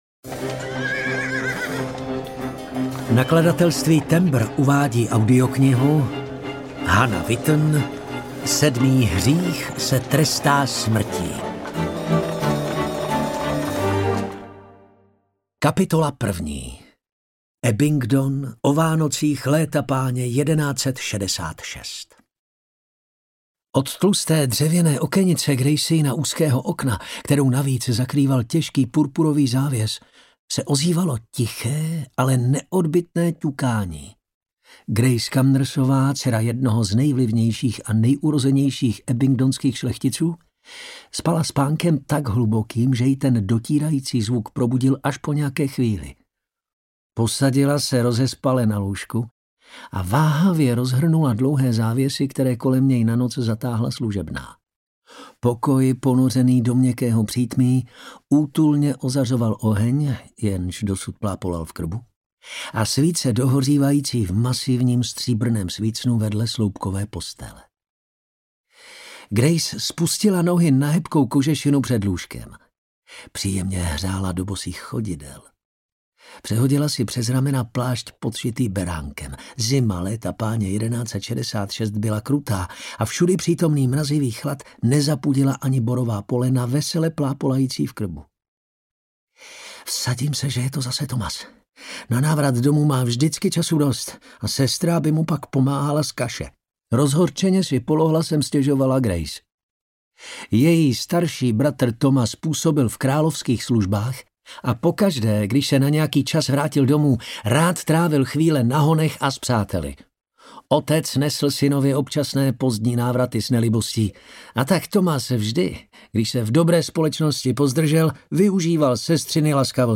Sedmý hřích se trestá smrtí audiokniha
Ukázka z knihy